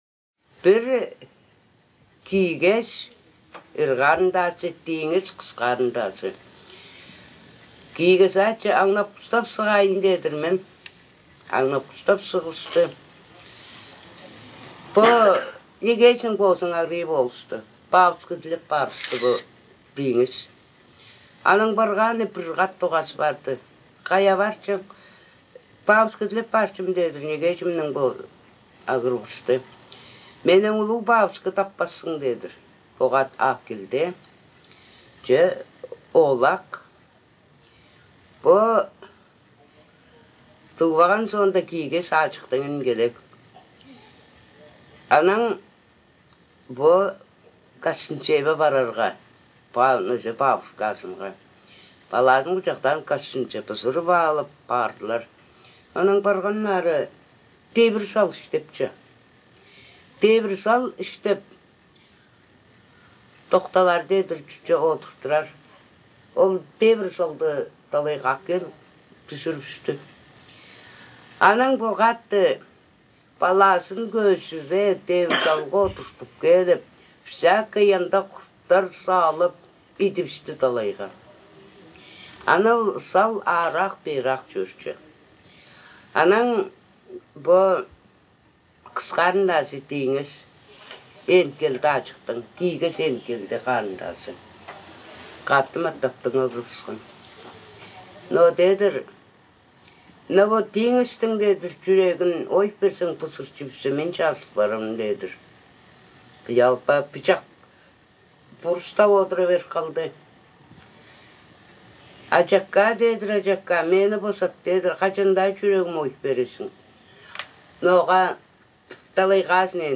Речь на шорском языке
1. Аудиозаписи речи.